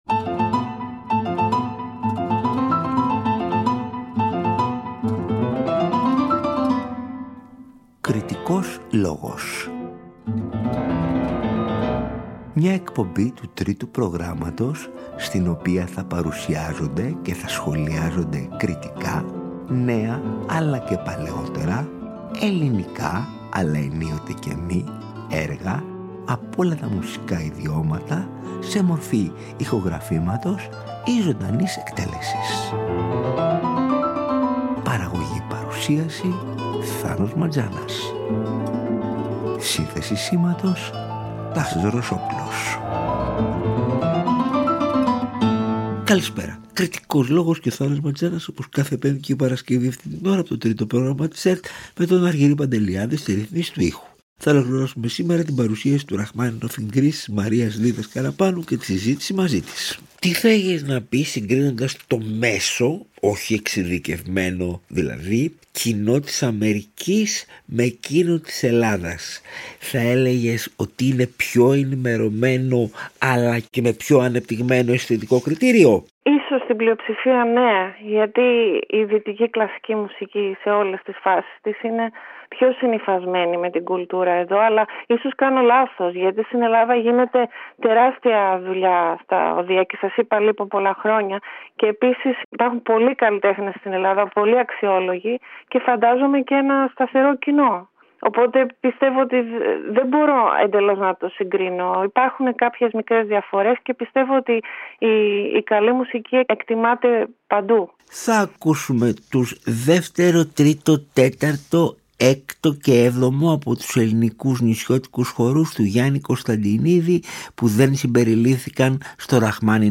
Από την οικία της στο Ντιτρόιτ του Μίσιγκαν